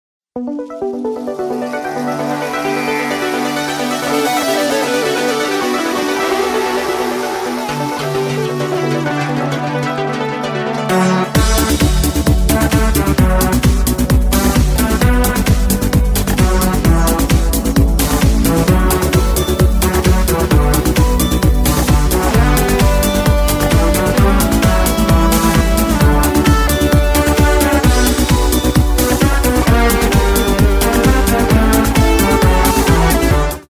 رینگتون ورزشی و هیجانی